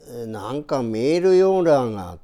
Aizu Dialect Database
Type: Statement
Final intonation: Falling
Location: Showamura/昭和村
Sex: Male